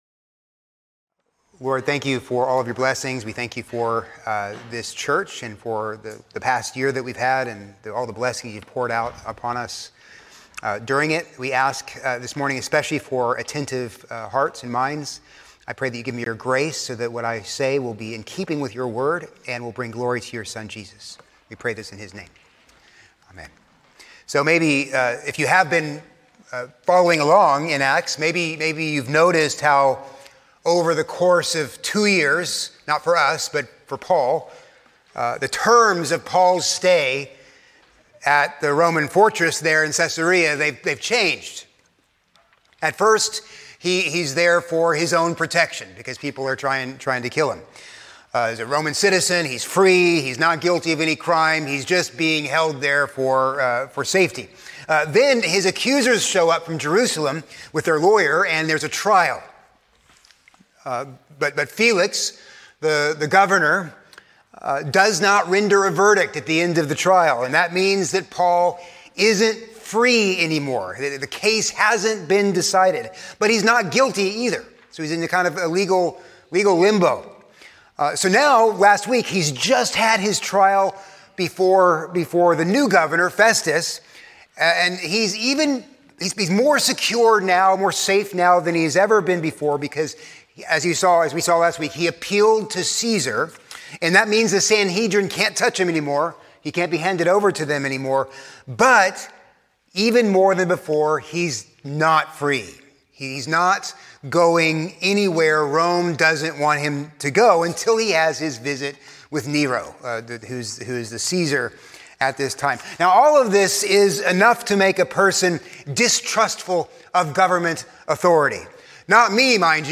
A sermon on Acts 25:13-27